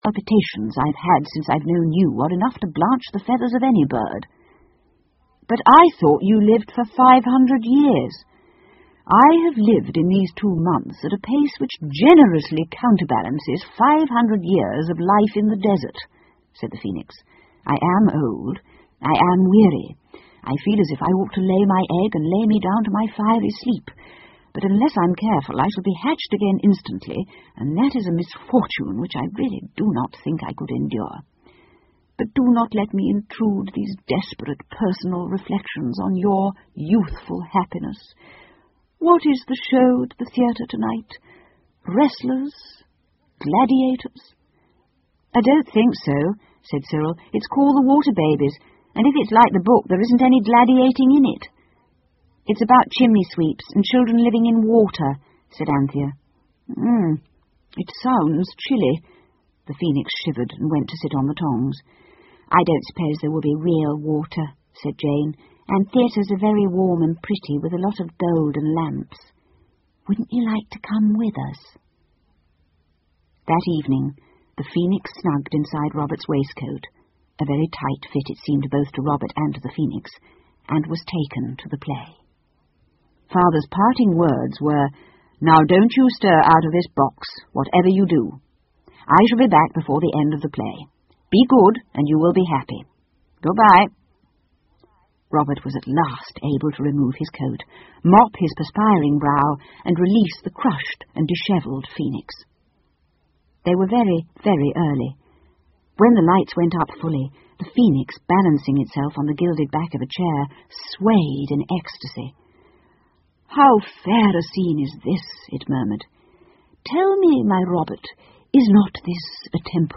凤凰与魔毯 The Phoenix and the Carpet 儿童英语广播剧 12 听力文件下载—在线英语听力室